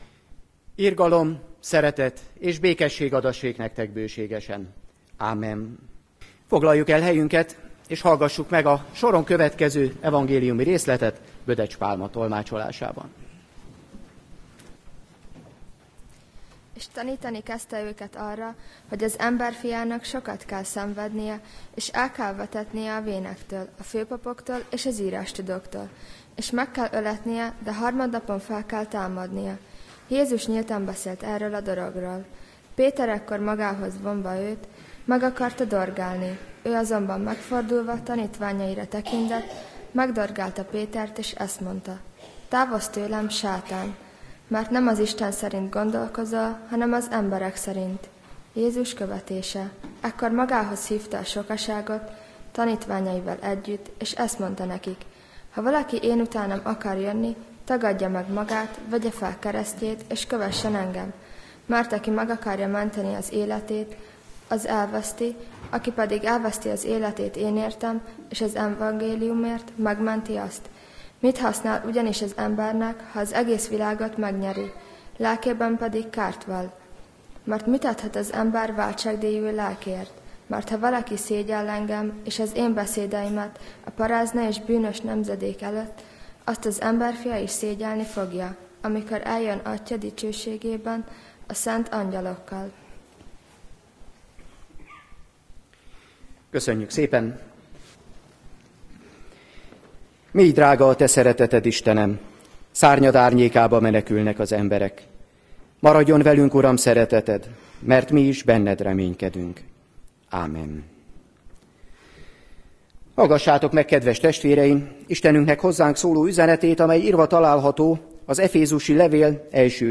Tatai Református Gyülekezet - Prédikáció sorozatok